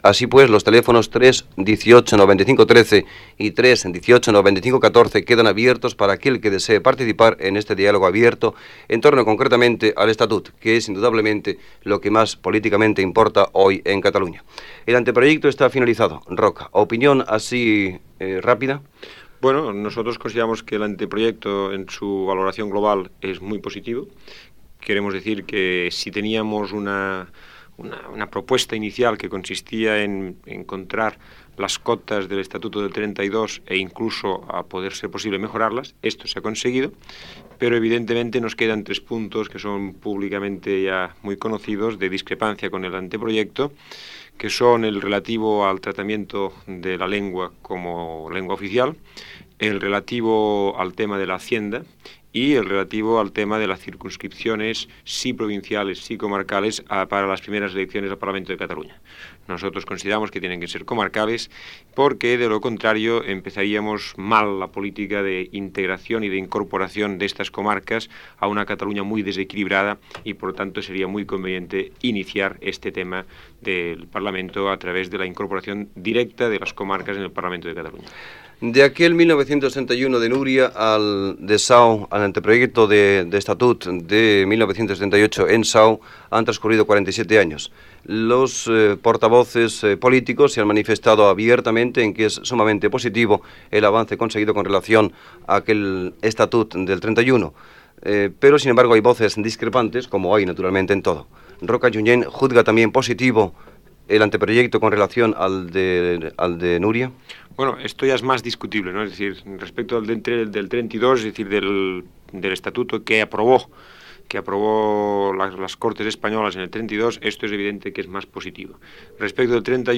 Telèfons de participació, entrevista al polític Miquel Roca i Junyent, sobre l'avantprojecte de l'Estatut de Catalunya. Hora, segueix l'entrevista i participació d'un oïdor